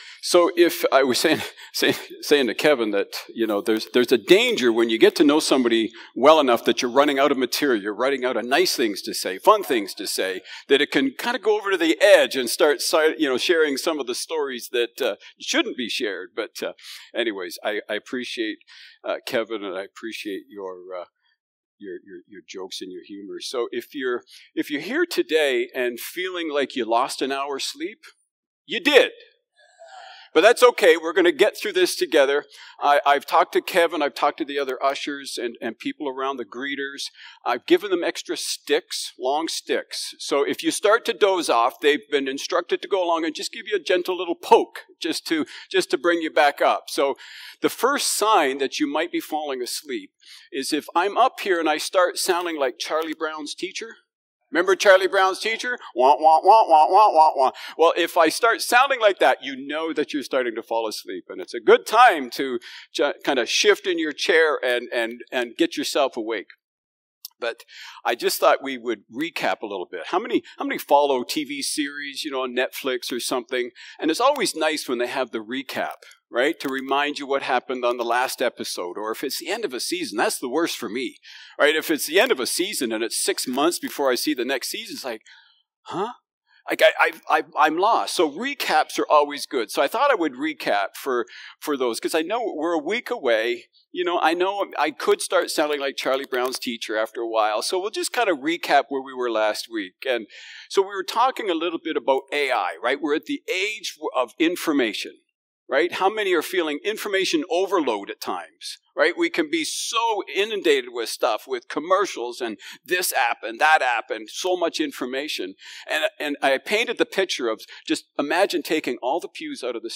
March-8-Sermon.mp3